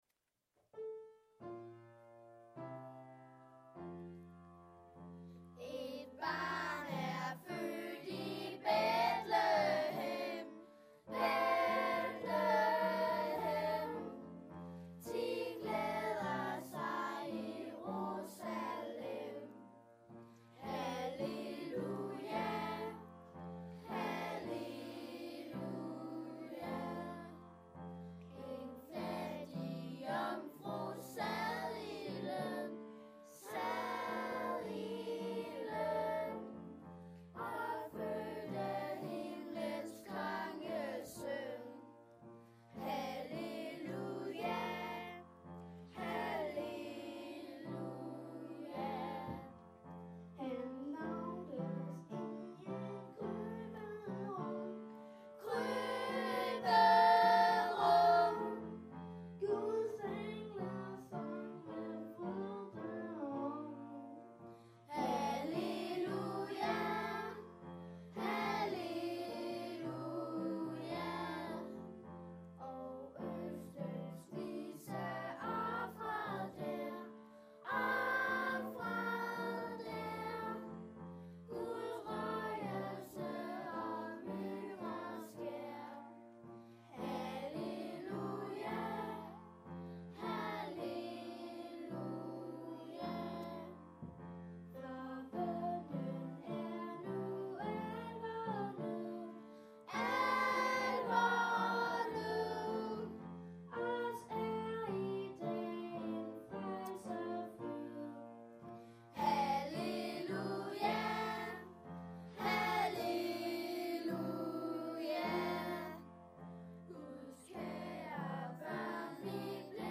4B sings an old danish christmas carol about Bethlehem and the child born there